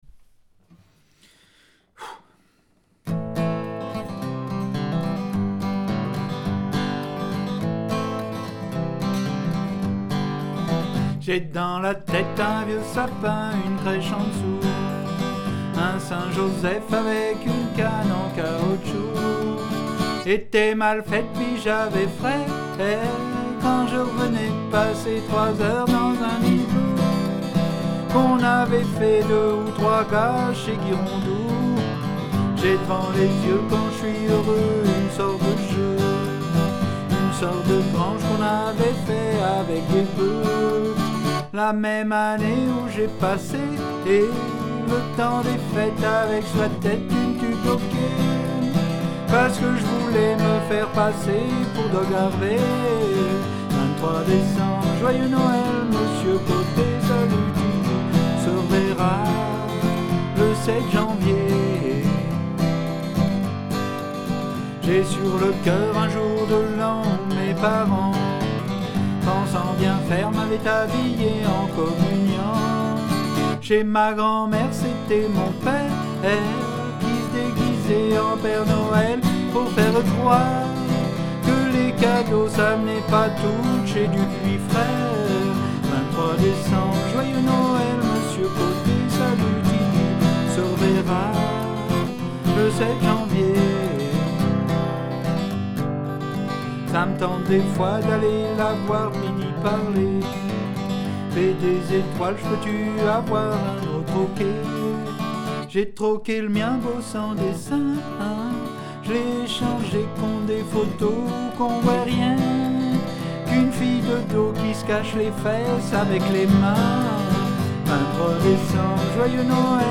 guitare n°2 :